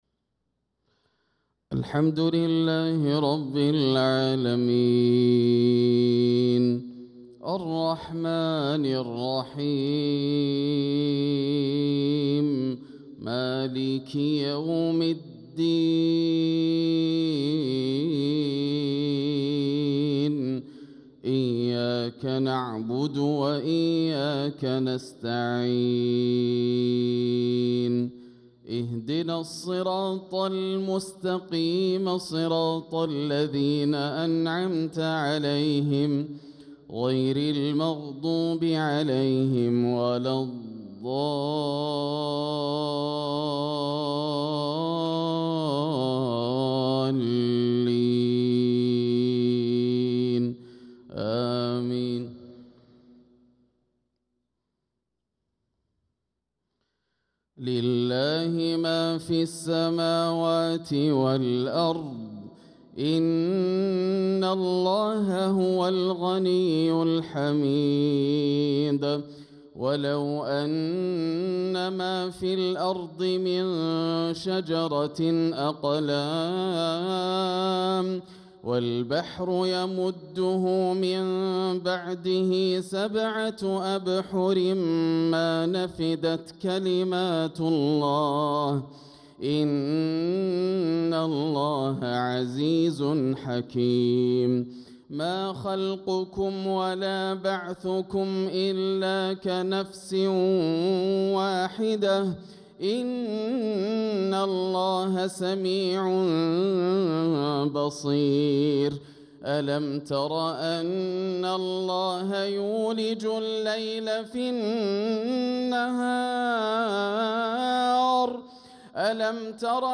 صلاة العشاء للقارئ ياسر الدوسري 17 ربيع الآخر 1446 هـ